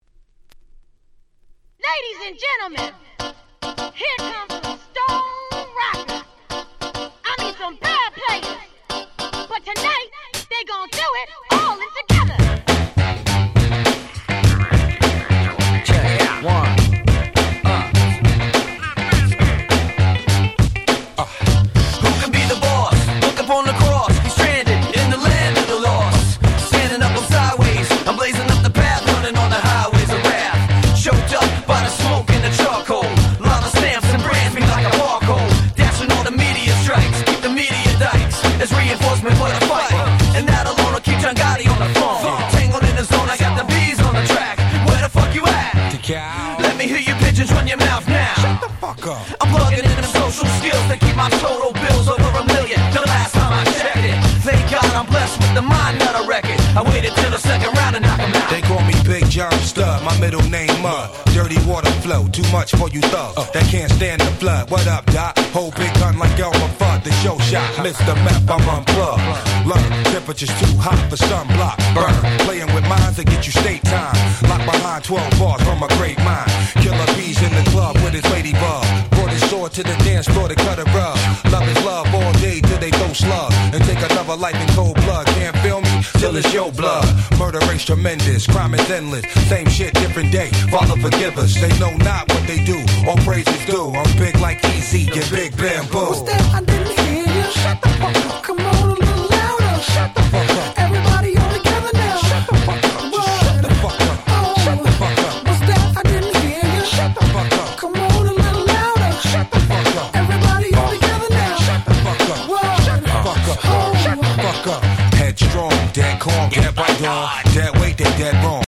01' Smash Hit Hip Hop !!